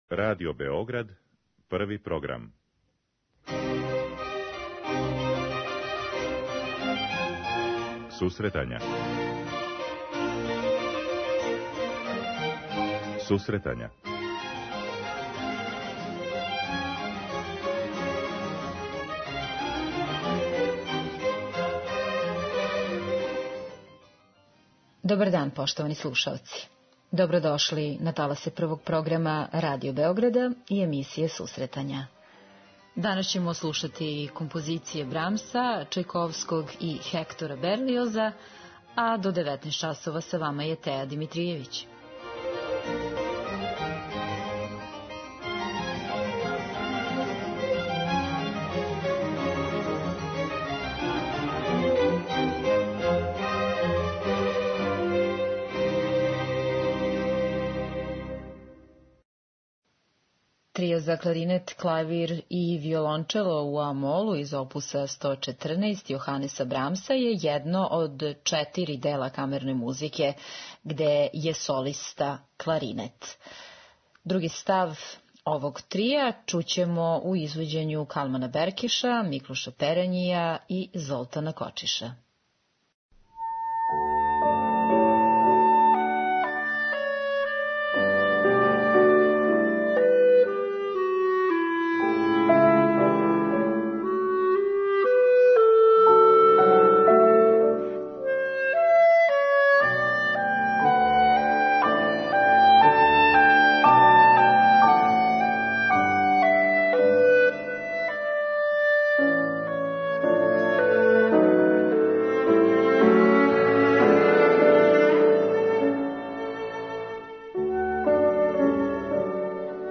У другој половини августа слушаћемо дела Јоханеса Брамса, Петра Чајковског и Хектора Берлиоза која су настала у другој половини 19. века.
Романтизам друге половине 19. века